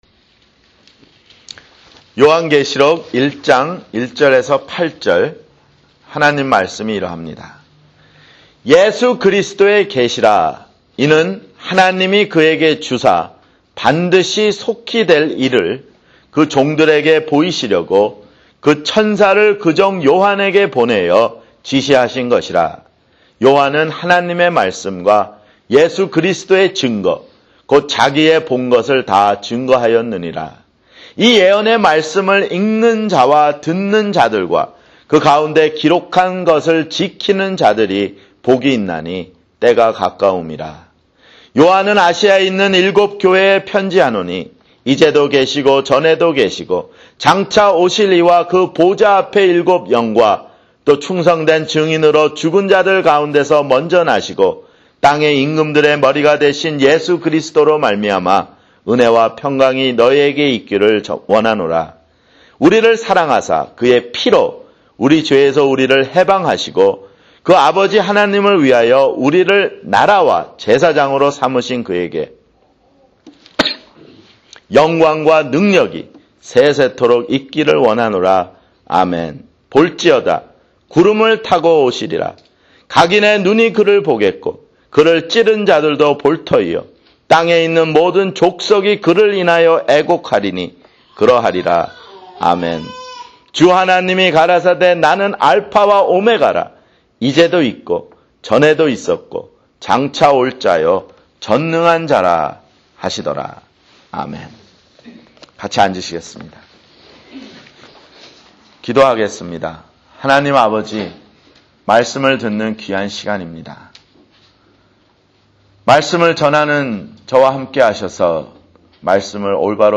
[주일설교] 요한계시록 (1)